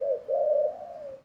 pgs/Assets/Audio/Animals_Nature_Ambiences/bird_pigeon_call_04.wav at master
bird_pigeon_call_04.wav